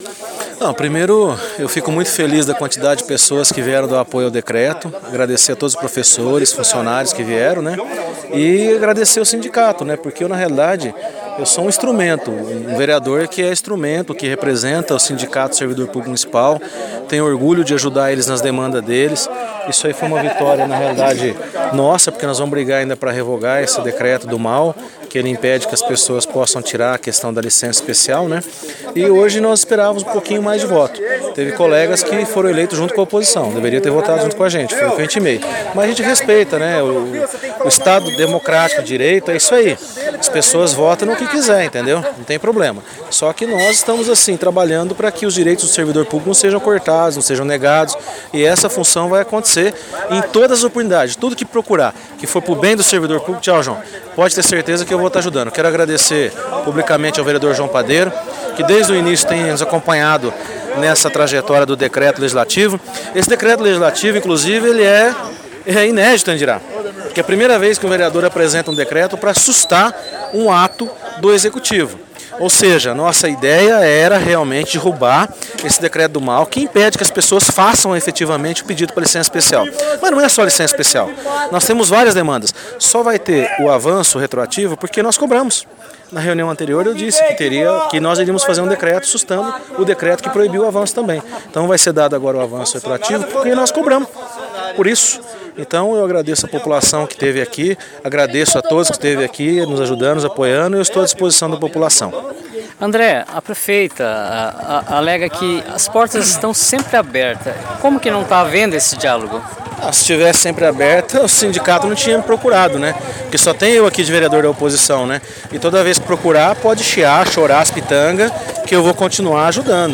O vereador André deu mais detalhes a reportagem da GAZETA 369 e da Rádio Cultura AM: